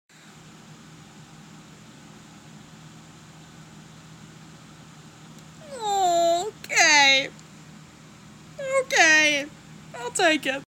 *happy noises*